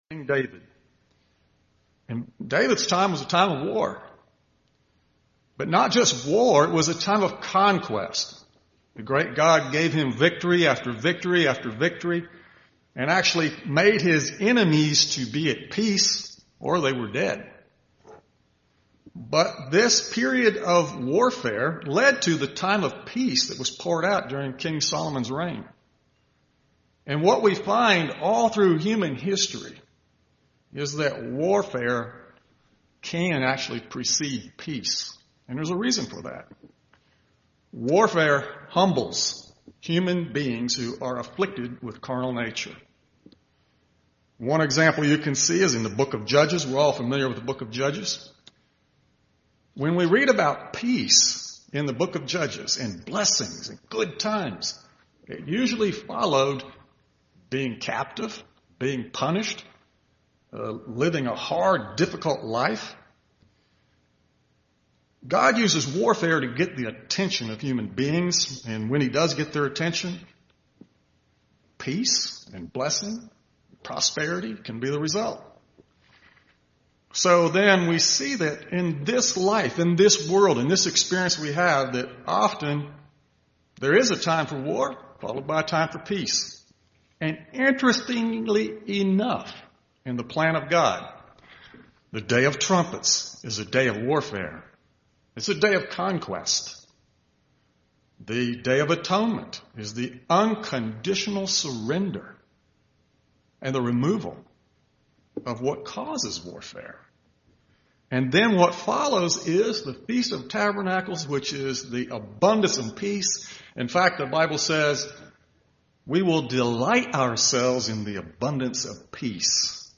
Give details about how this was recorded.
Given in Birmingham, AL